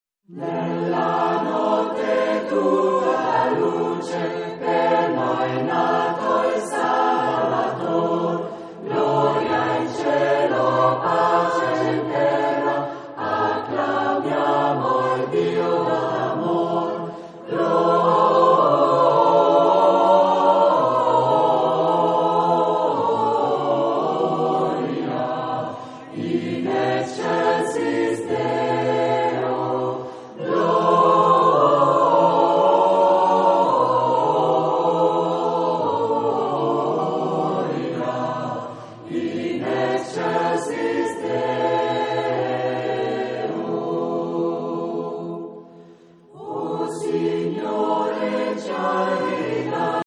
un gruppo vocale amatoriale composto da circa 25 elementi
Tradizionale francese